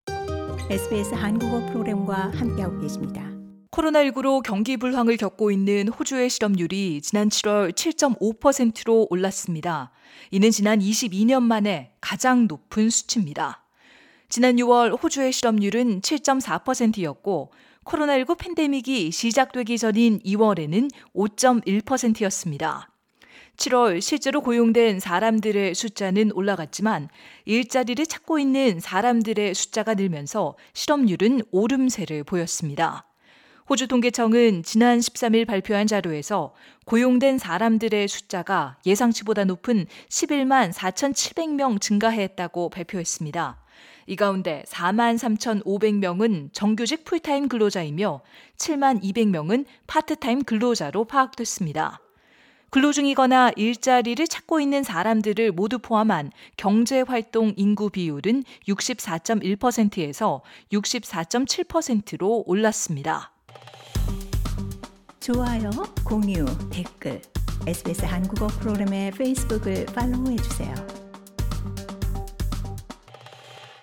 audio_news-jobless-korean.mp3